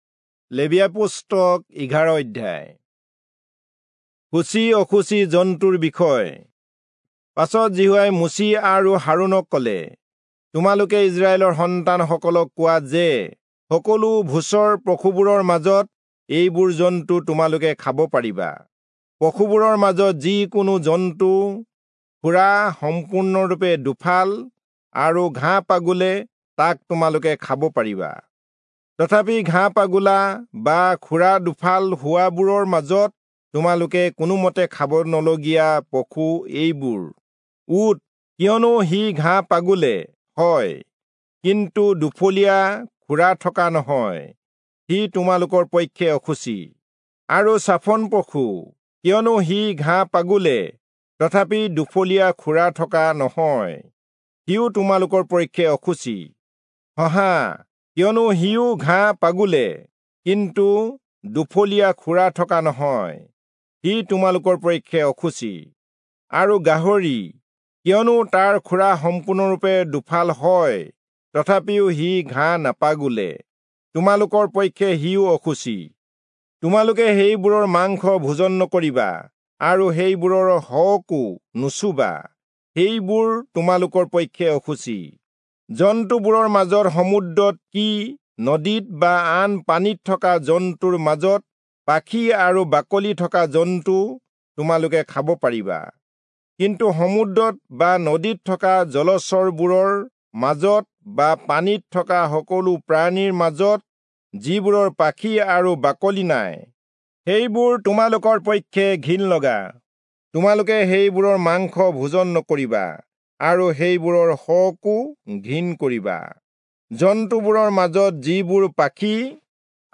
Assamese Audio Bible - Leviticus 6 in Mhb bible version